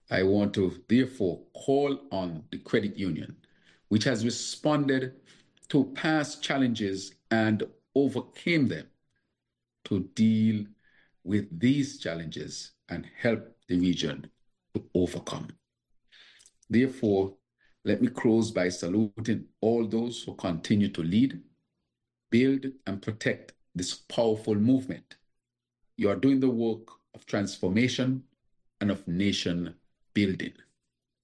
In a virtual address at the opening ceremony, Prime Minister of Saint Kitts and Nevis, the Hon. Dr. Terrance Drew, underscored the importance of collaboration in confronting a “polycrisis” of climate change, inflation, and geopolitical instability, urging credit unions to lead regional resilience efforts.